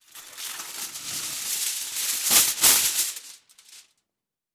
Звуки фольги